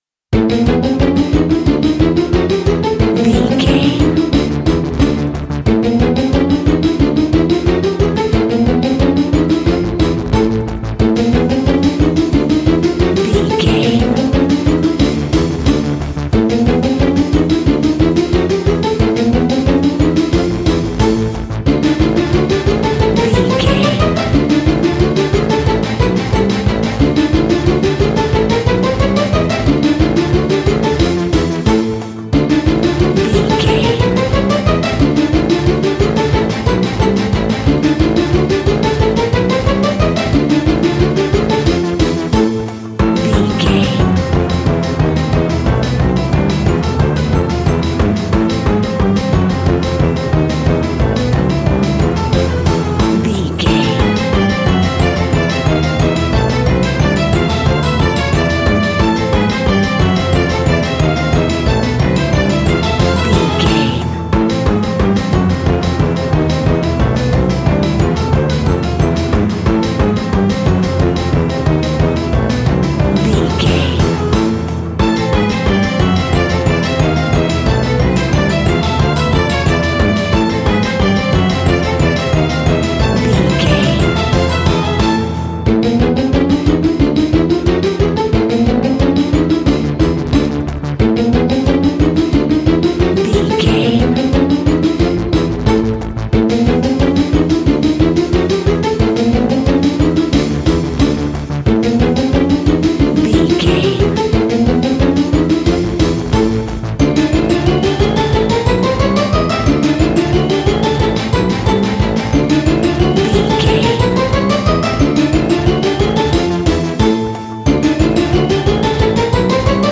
Aeolian/Minor
scary
ominous
suspense
eerie
strings
brass
synthesiser
percussion
piano
spooky
horror music